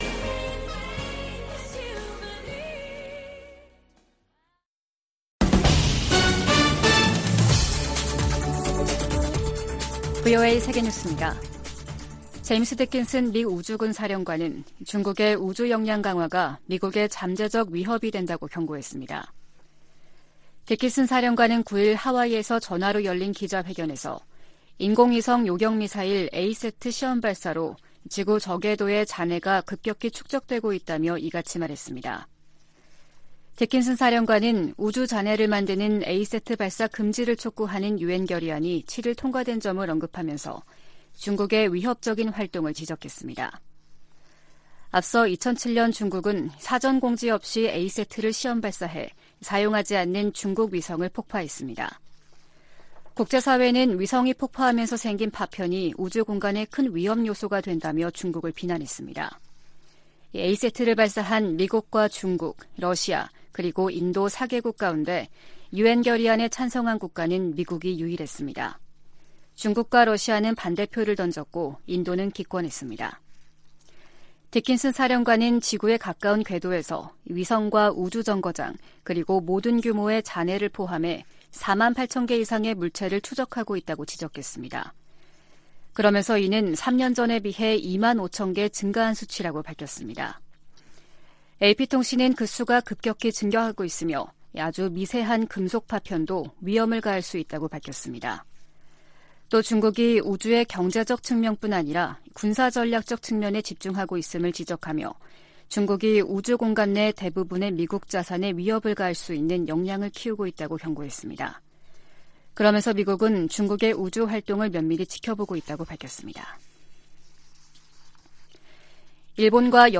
VOA 한국어 아침 뉴스 프로그램 '워싱턴 뉴스 광장' 2022년 12월 10일 방송입니다. 7차 핵실험 가능성 등 북한 김씨 정권의 핵 위협이 미국의 확장억지와 핵우산에 도전을 제기하고 있다고 백악관 고위관리가 지적했습니다. 국무부 대북특별대표가 중국 북핵 수석대표와의 화상회담에서 대북 제재 이행의 중요성을 강조했습니다.